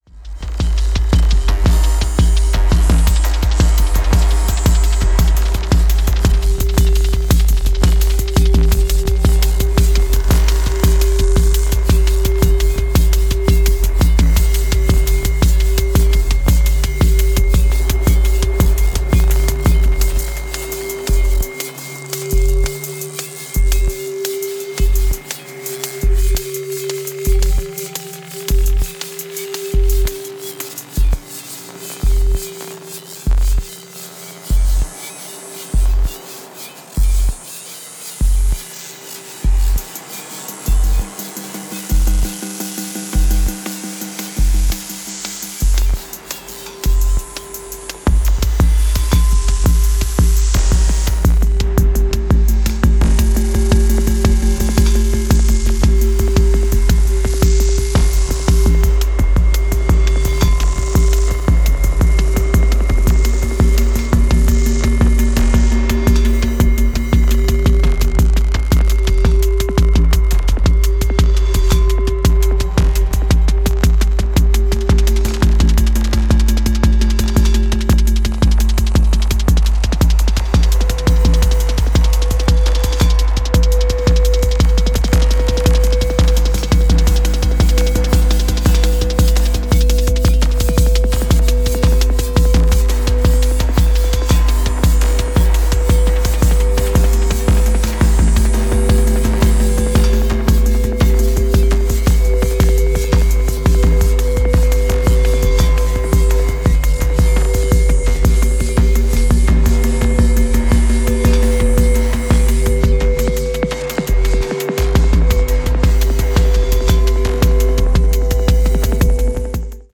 IDM , Jungle